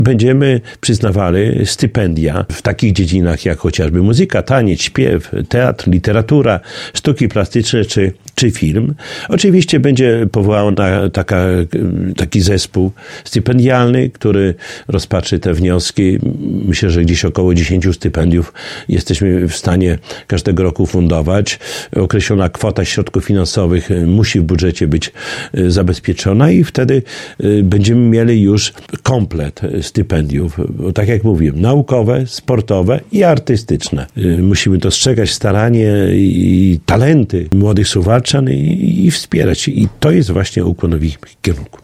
Uzdolnioną artystycznie młodzież chce wspierać suwalski ratusz. W związku z tym miasto zamierza przyznać stypendia, między innymi, w takich dziedzinach, jak taniec, muzyka czy film. Szczegóły przedstawił Czesław Renkiewicz, prezydent Suwałk.